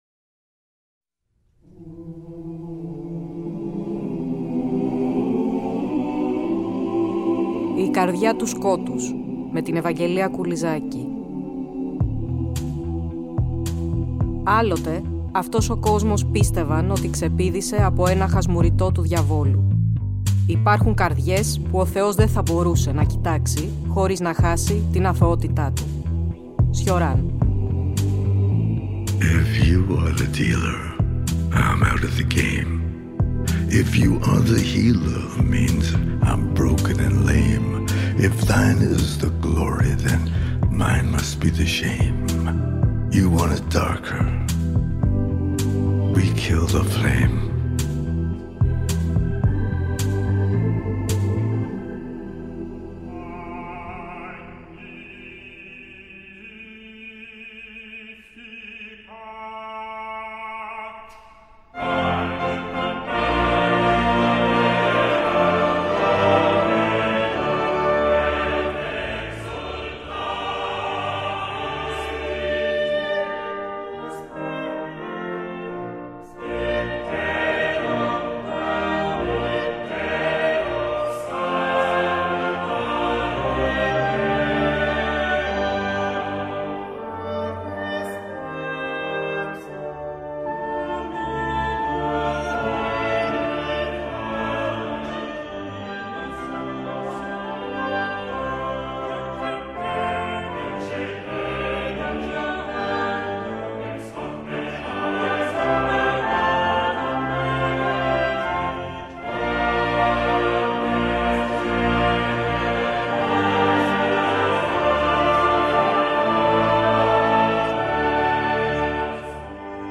Πλαισιώνουμε μουσικά (και) με αποσπάσματα από το «υβριδικό» έργο του Hector Berlioz (“ légende dramatique en quatre parties “, το χαρακτήριζε ο ίδιος) “La Damnation de Faust” .